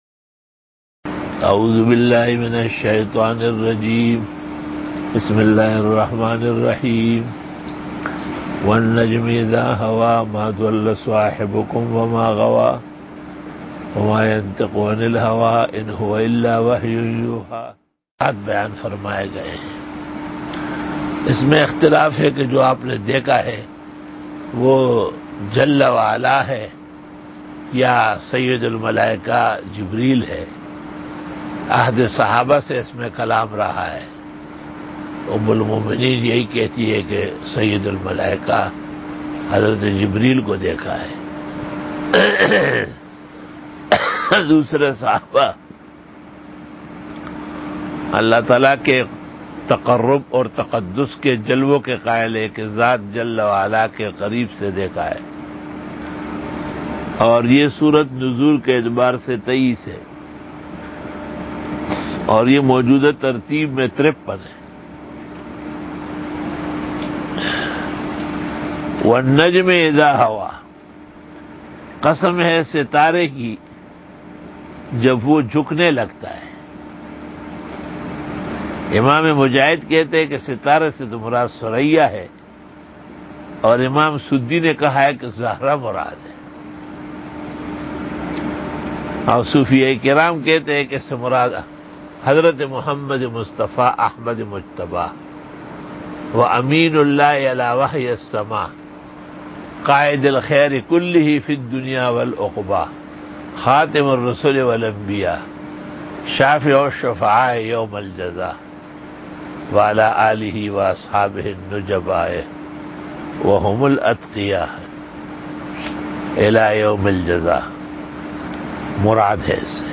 Dora-e-Tafseer 2020 Bayan